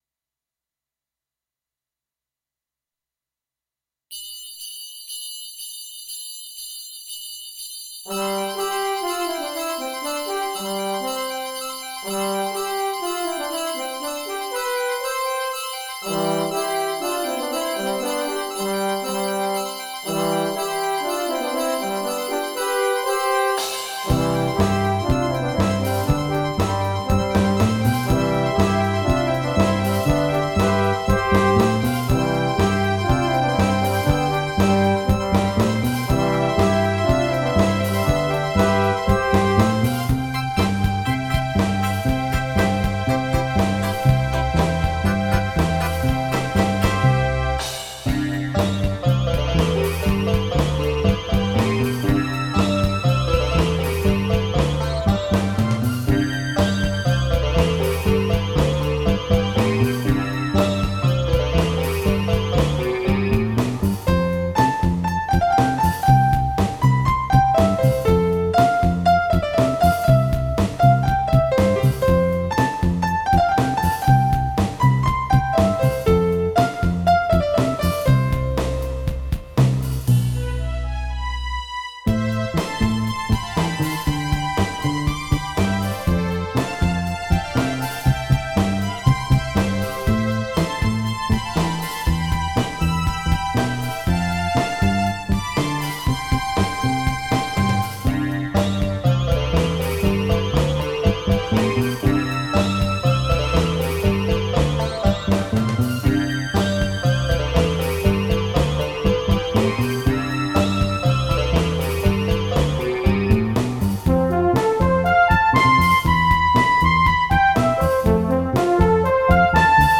始まりが、前の曲と同じく鈴のような音 四部刻みで始まってますが